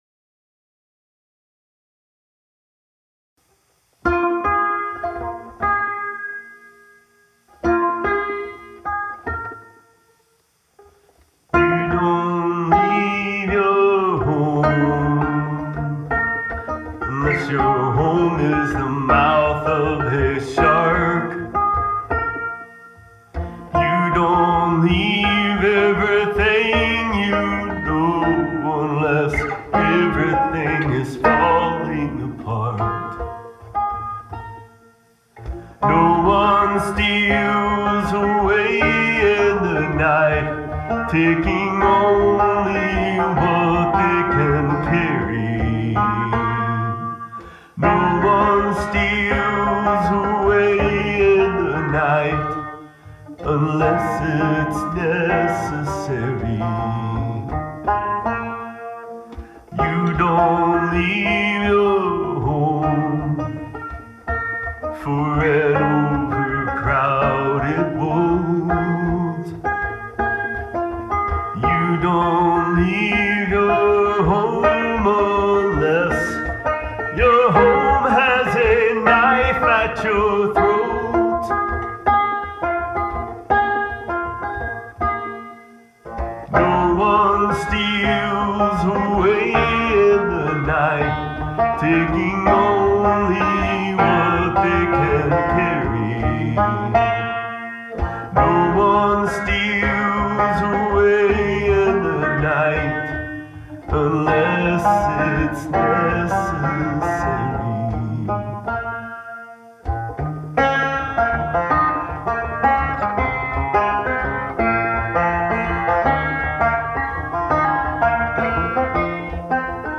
Folk, Banjo This song was inspired by the by the beautiful, heartbreaking poem, home, by Warsan Shire, from her equally beautiful book, Bless the Daughter Raised by a Voice in Her Head: Poems.
The banjo sounds like a completely different instrument when it is picked slowly and intentionally like this.
It contrasts the banjo beautifully